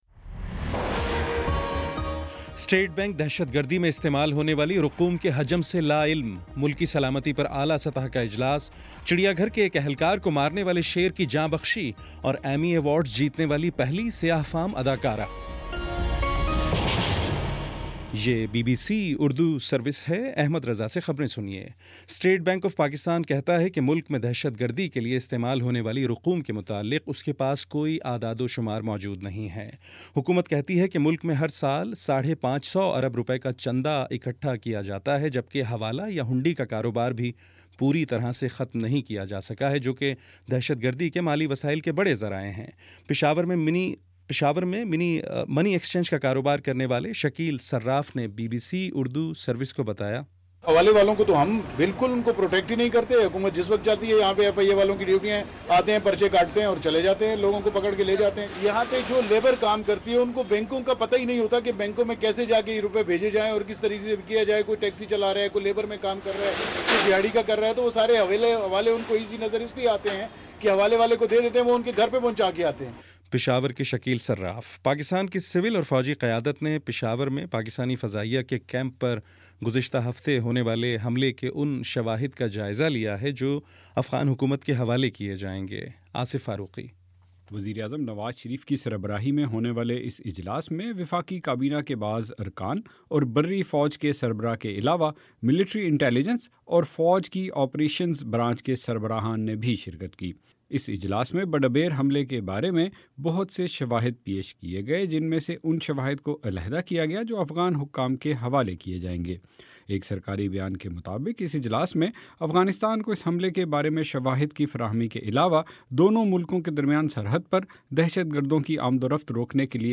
ستمبر21 : شام پانچ بجے کا نیوز بُلیٹن